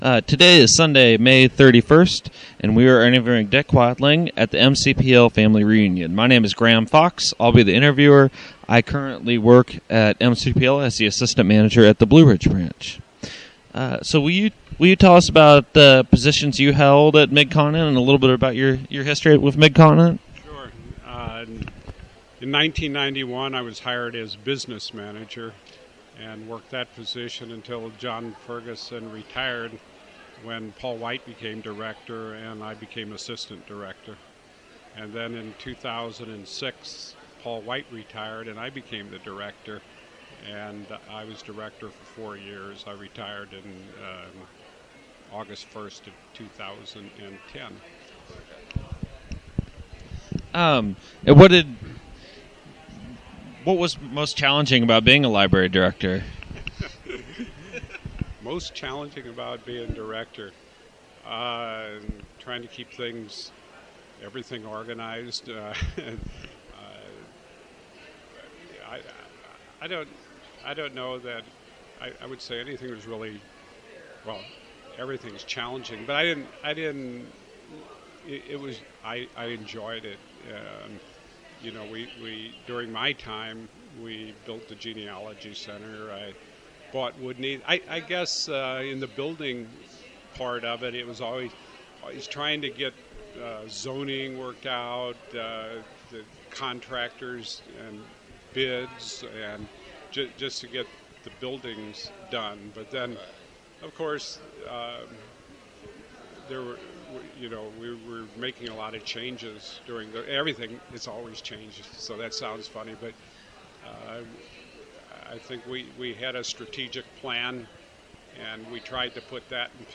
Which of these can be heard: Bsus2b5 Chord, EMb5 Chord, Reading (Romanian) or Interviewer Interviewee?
Interviewer Interviewee